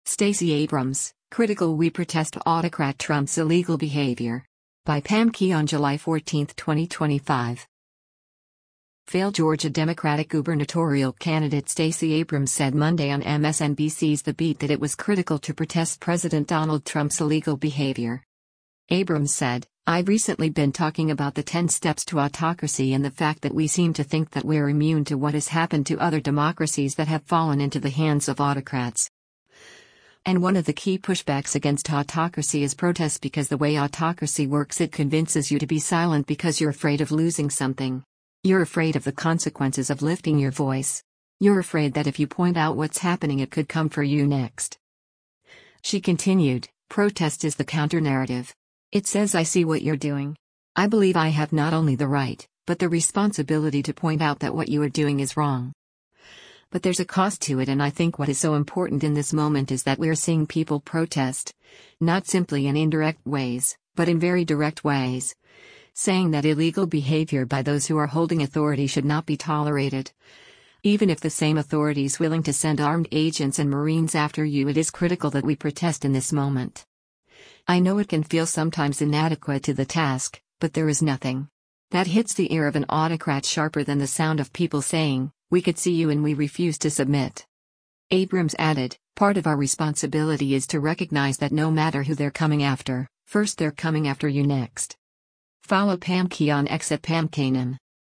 Failed Georgia Democratic gubernatorial candidate Stacey Abrams said Monday on MSNBC’s “The Beat” that it was “critical” to protest President Donald Trump’s “Illegal behavior.”